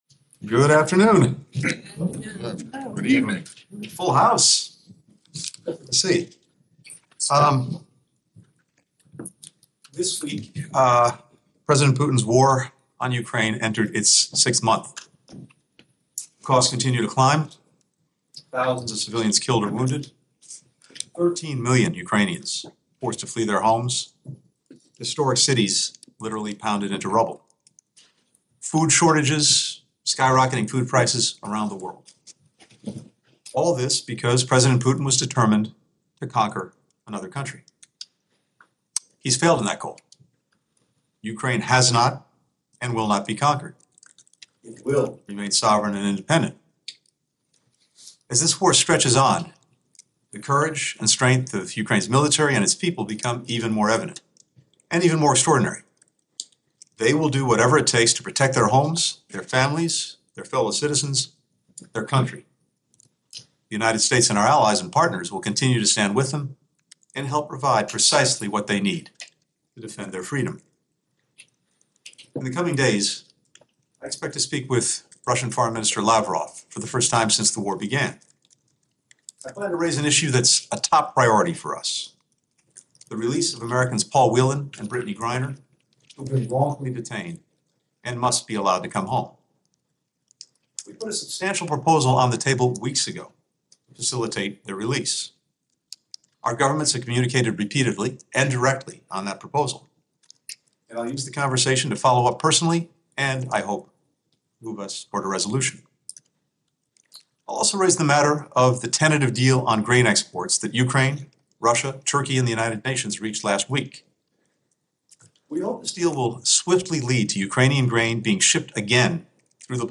Antony Blinken - Press Availabity 27 July 2022
Press Briefing Room, U.S. Department of State, Washington, D.C.